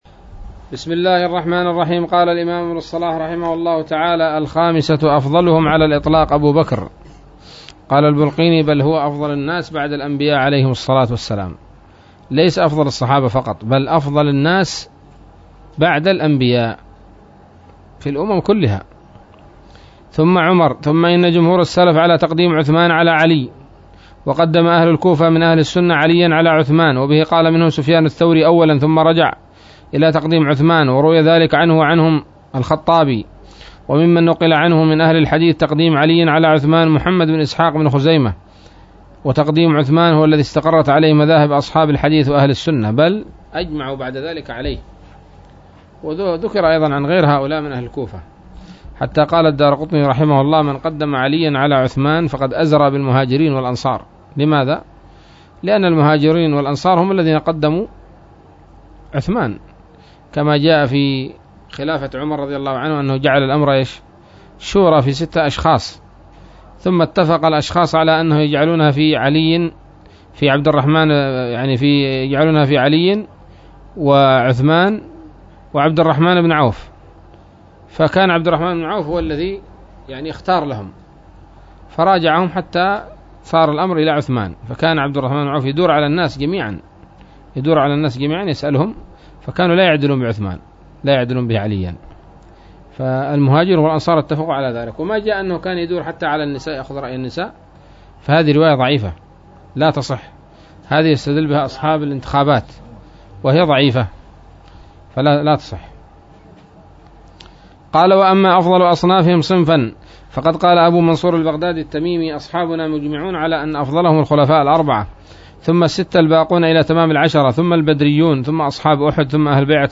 الدرس التاسع والتسعون من مقدمة ابن الصلاح رحمه الله تعالى